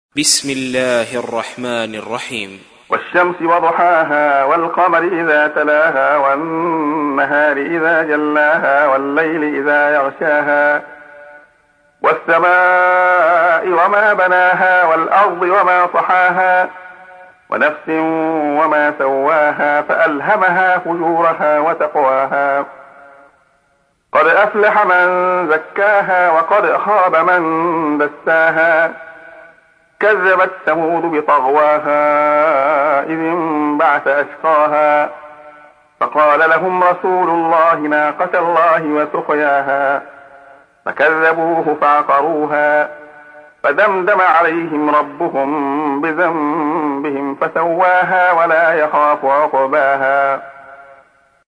تحميل : 91. سورة الشمس / القارئ عبد الله خياط / القرآن الكريم / موقع يا حسين